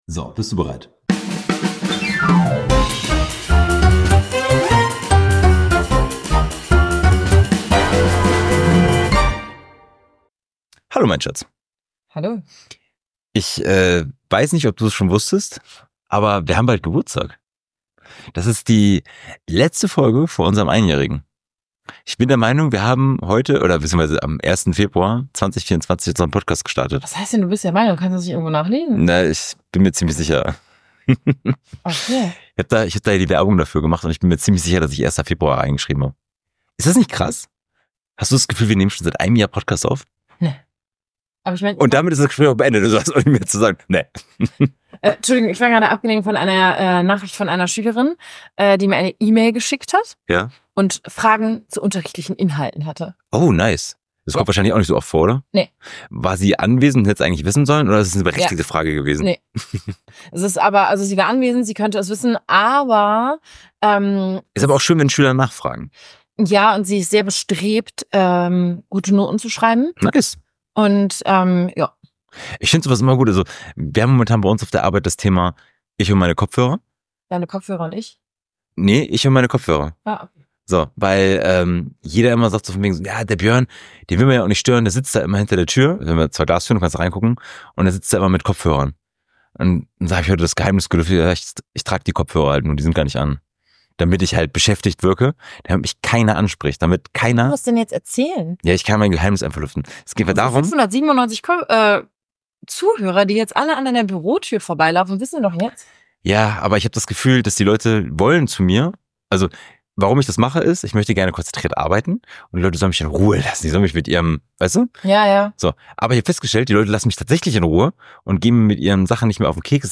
Beschreibung vor 1 Jahr Bevor ich hier irgendwas hinschreibe: Es kann sein, dass der Sound nicht ganz optimal ist. Liegt daran, dass ein gewisser Herr das Mikro nicht richtig eingesteckt hat.